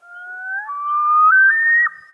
sounds_loon_02.ogg